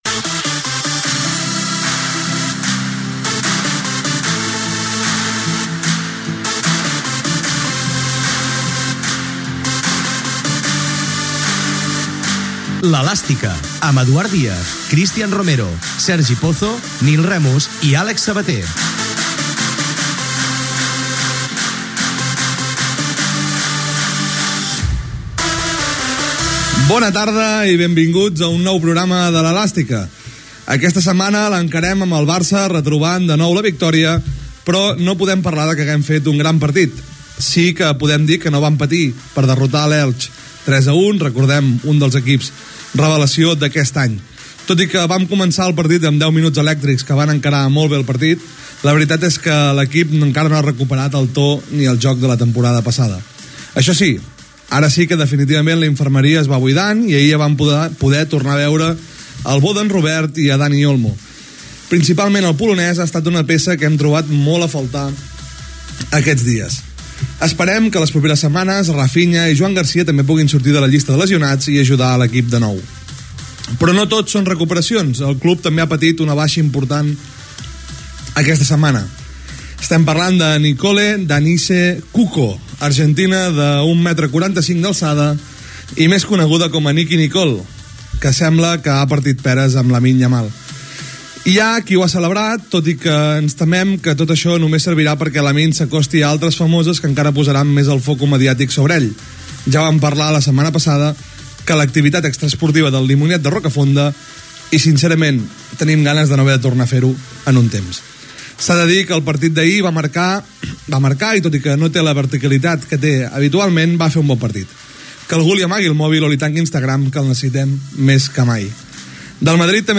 Careta del programa, actualitat de l'equip de futbol masculí del Futbol Club Barcelona
Esportiu